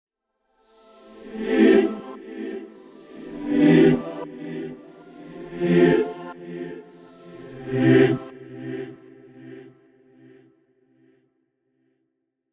Reverieの最大の特徴は、「ブダペスト・スコアリング・クワイア」と共に録音されていることです。
美しいクワイアサウンドから実験的な音響表現まで、その多彩な表現力を実際にいくつかのプリセットで聴いてみてください。
このように、神秘的な合唱の美しさに加えて、CUBEならではの実験的な加工が施されたプリセットも収録されています。